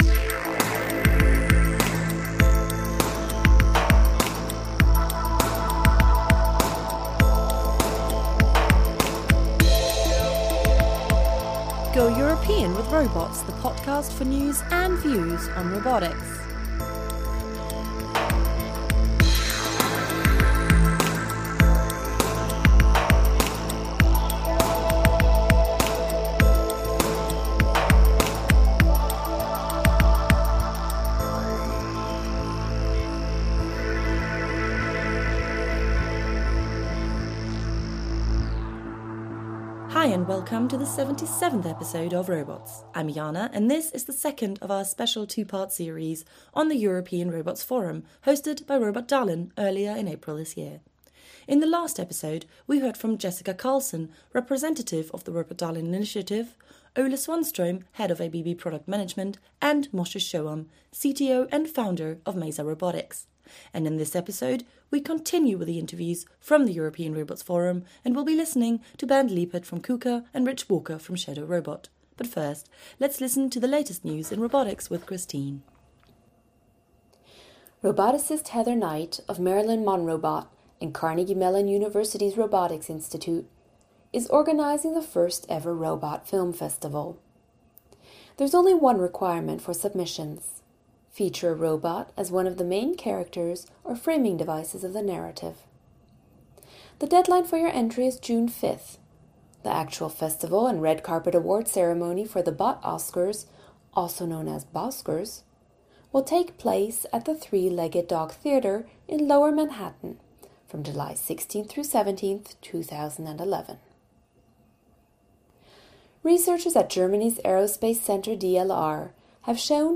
The European Robotics Forum, jointly organized by the European Robotics Technology Platform (EUROP) and the European Robotics Research Network (EURON), was hosted this year on April 6-8, in Västerås, Sweden by Robotdalen. Thanks to an invitation by EUnited Robotics, we got a chance to be there and talk to some of Europe’s major players in the field, from both industry and research.